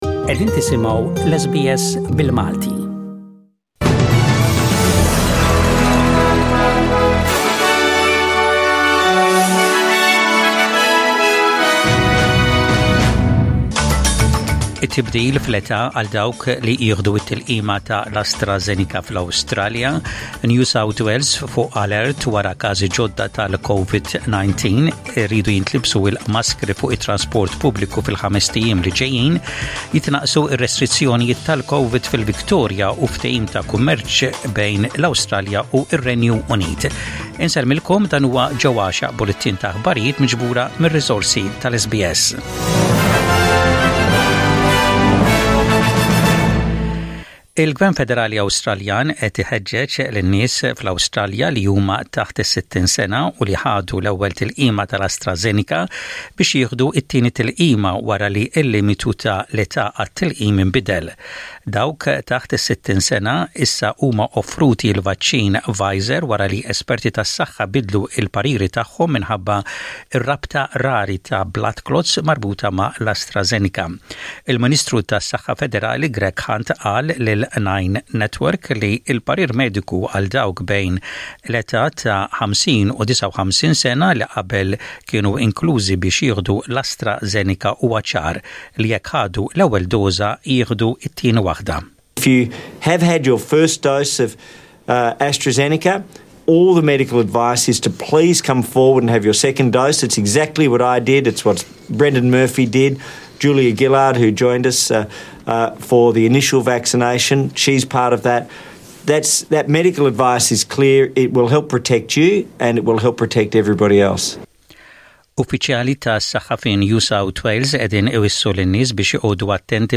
SBS Radio | Aħbarijiet bil-Malti: 18/06/21